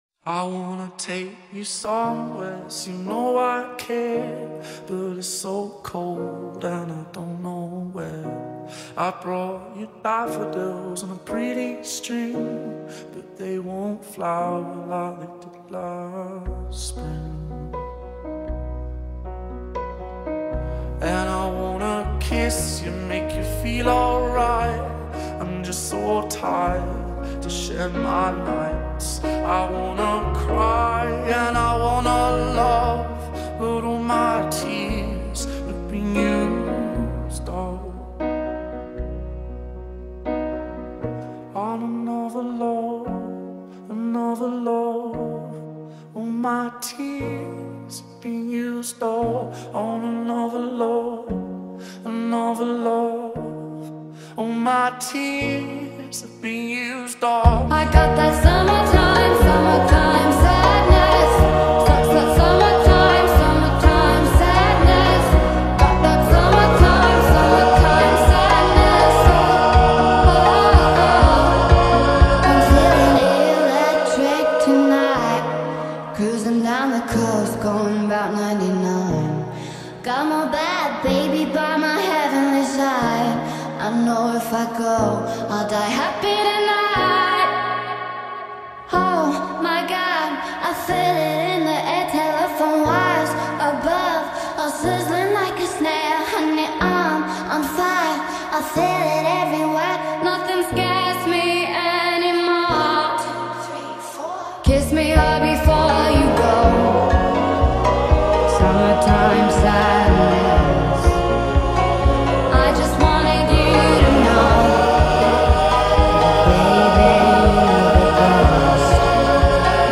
غمگین
عاشقانه